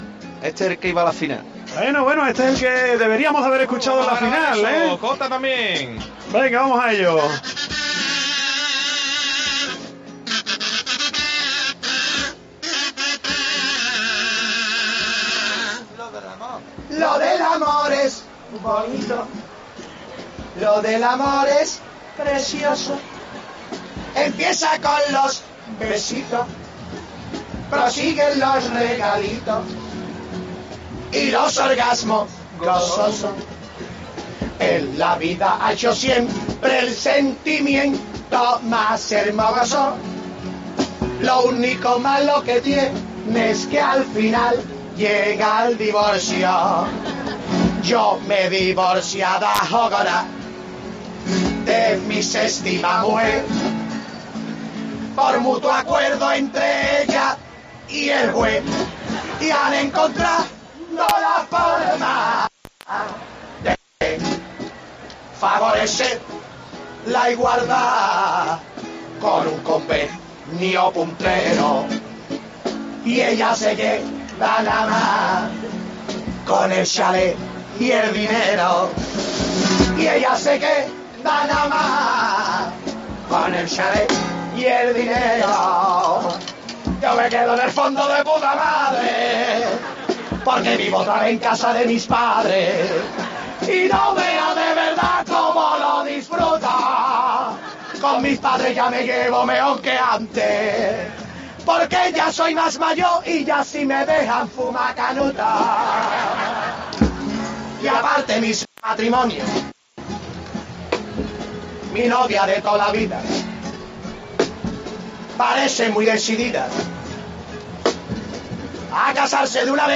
Carnaval